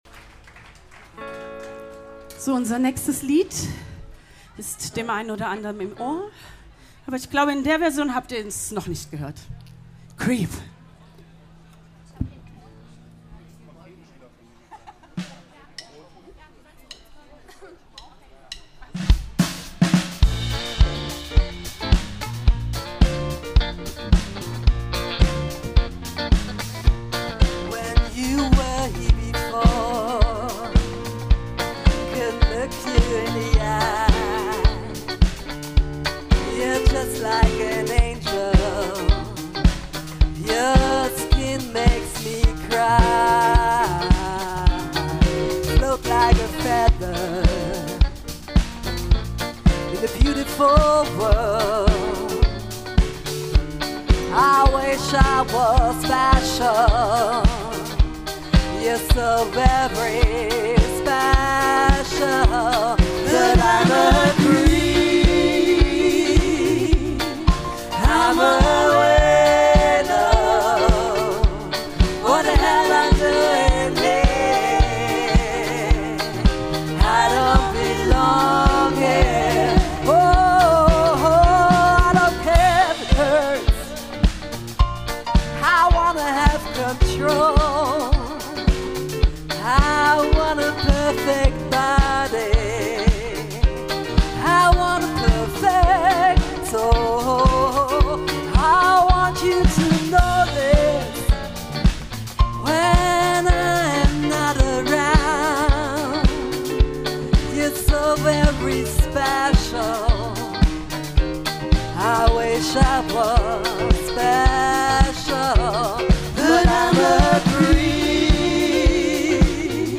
· Genre (Stil): Soul
· Kanal-Modus: stereo · Kommentar